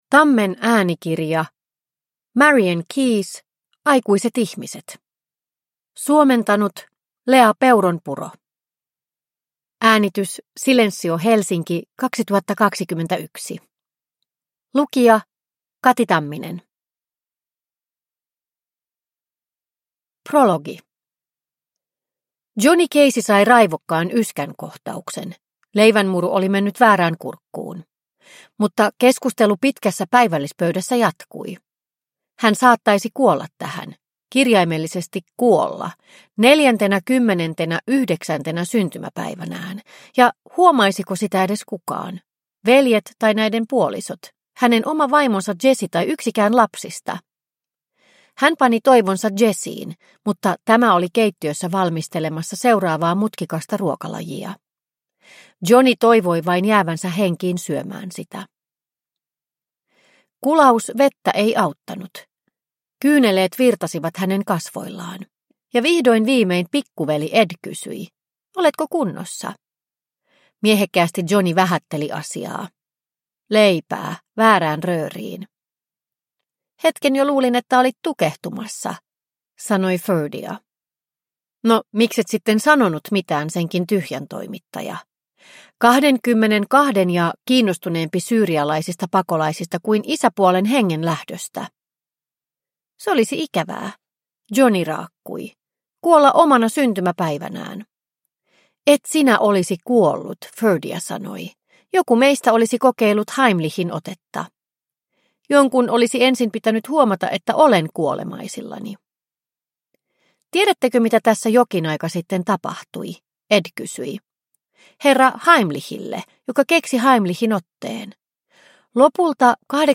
Aikuiset ihmiset – Ljudbok – Laddas ner